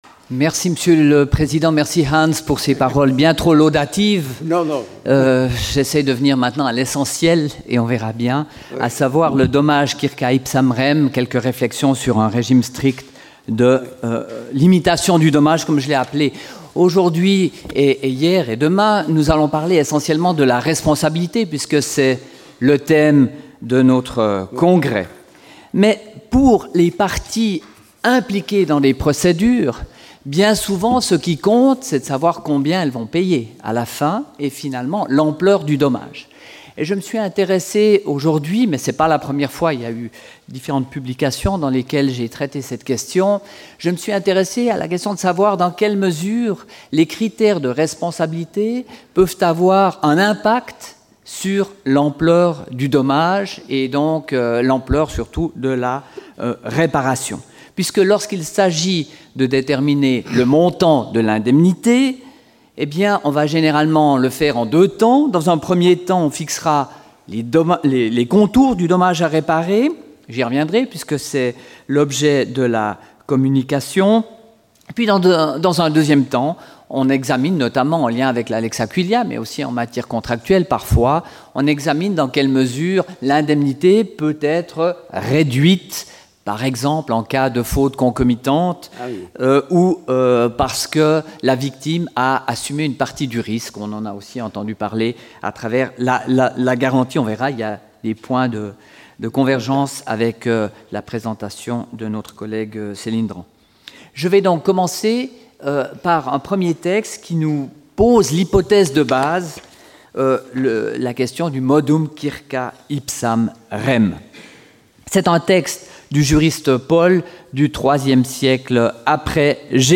Du 1er au 4 juin dernier se sont déroulées à la Faculté de Droit de Tours, les Journées internationales de la Société d'Histoire du Droit, association scientifique internationale plus que centenaire. Le thème qui avait été proposé par notre Faculté était la Responsabilité.
Près de 180 auditeurs furent présents pour entendre 63 communicants d'une dizaine de nationalités différentes (française, espagnole, italienne, hollandaise, belge, polonaise, allemande, suisse, autrichienne, portugaise, hongroise et bulgare).